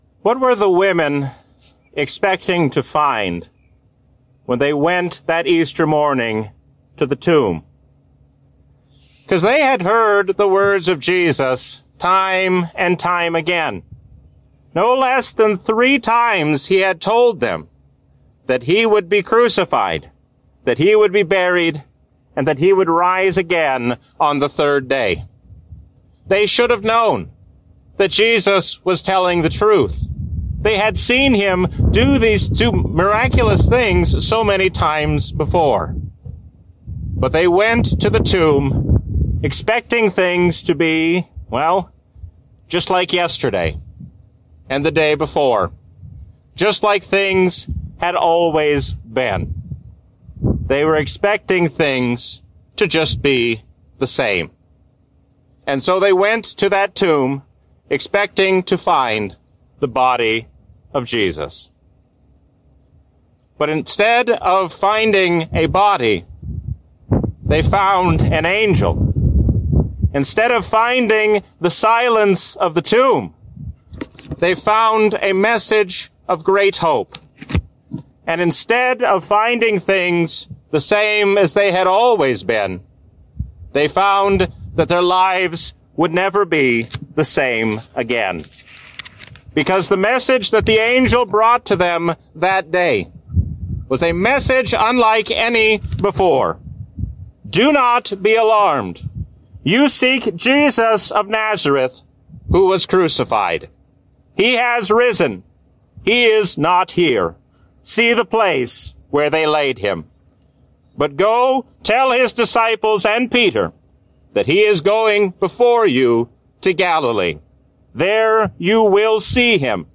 A sermon from the season "Trinity 2024." The ascension of Christ strengthens us to carry out the work of the Church while we wait for His coming.